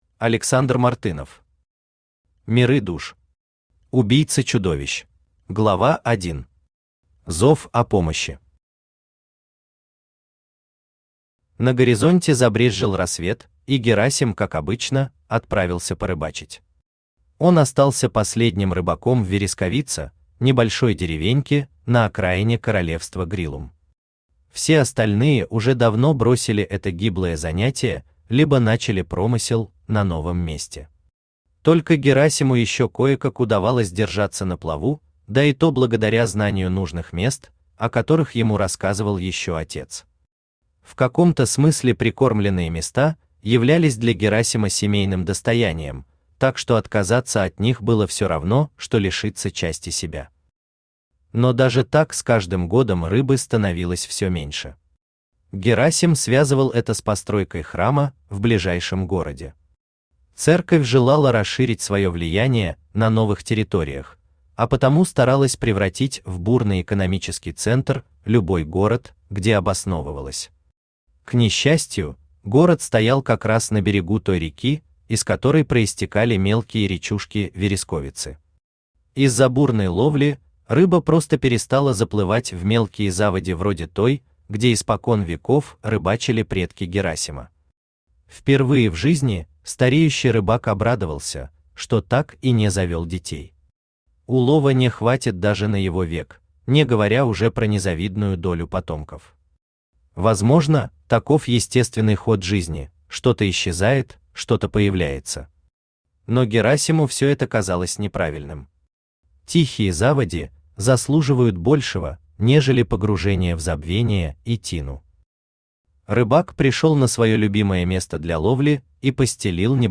Аудиокнига Миры душ. Убийца чудовищ | Библиотека аудиокниг
Убийца чудовищ Автор Александр Мартынов Читает аудиокнигу Авточтец ЛитРес.